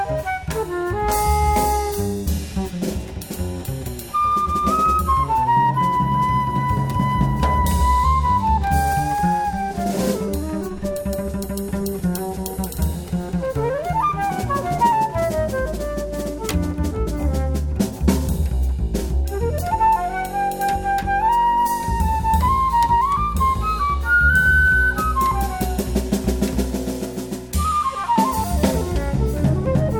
ens. voc. & instr.